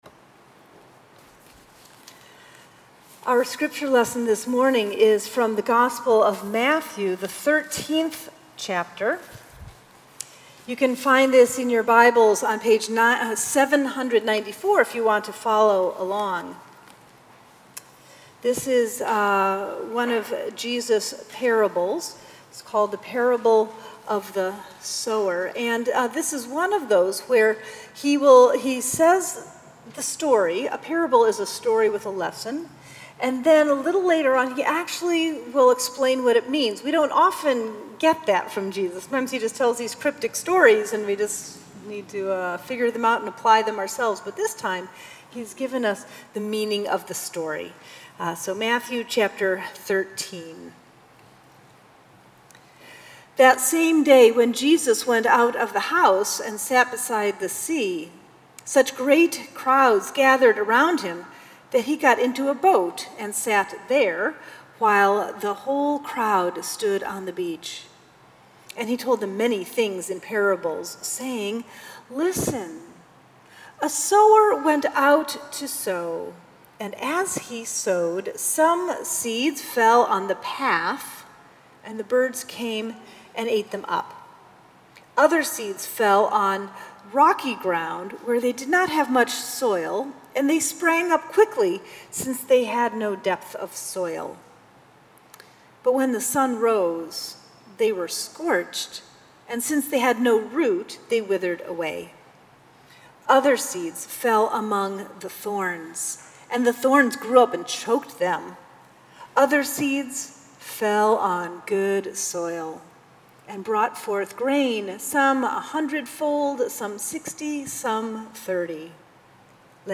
Sermons at Union Congregational Church
July 16, 2017 Sixth Sunday after Pentecost